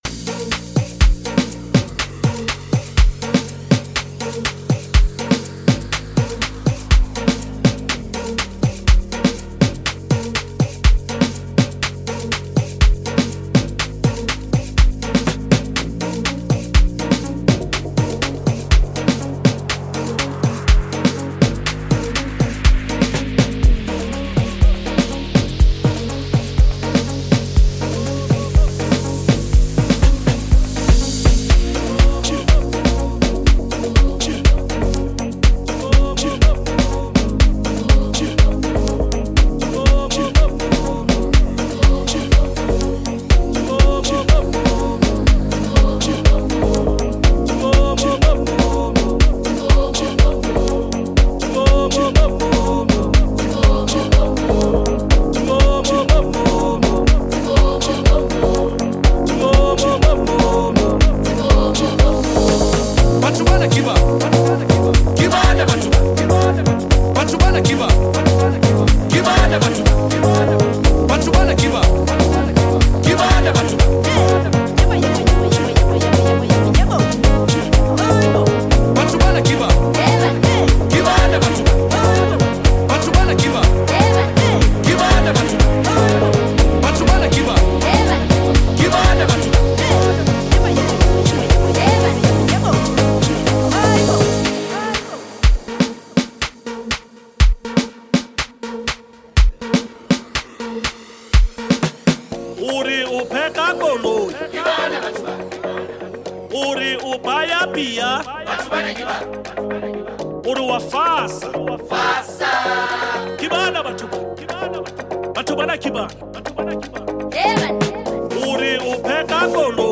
Afro House music